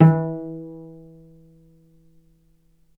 cello
vc_pz-E3-ff.AIF